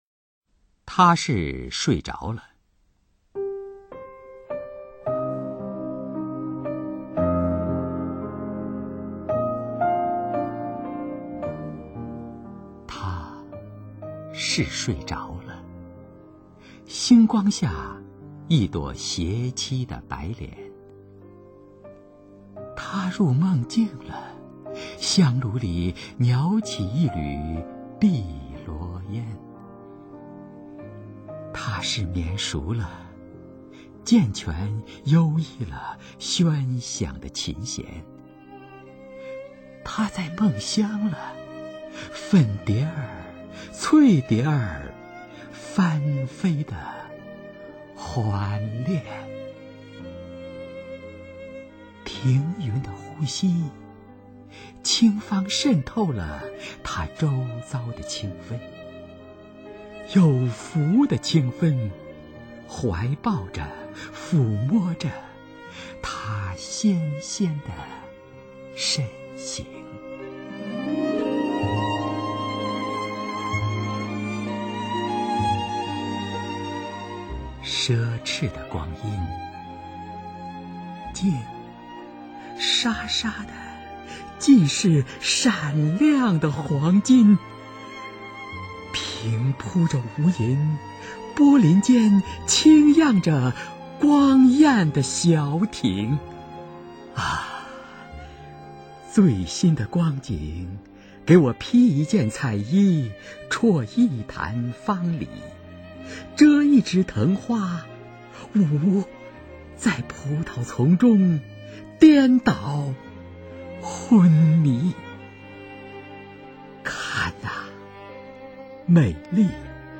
首页 视听 经典朗诵欣赏 中国百年经典诗文配乐诵读：光与色、情与景、人与事